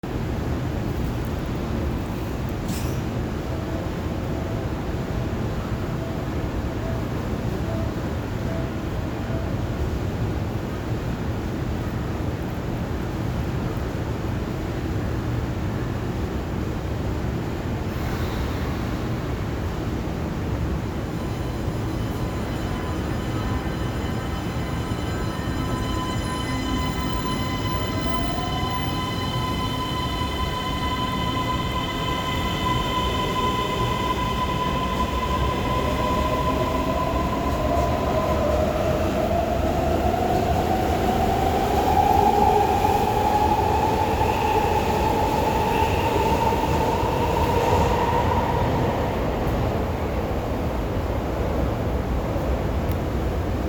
・AE形走行音(外から)
発車（空港第2ビルにて）
京成ではおなじみの東洋IGBTですが、3000形や3100形のそれとはモーター音が異なり、近い音を出す車両を挙げるなら京阪3000系等に類似していると言えそうです。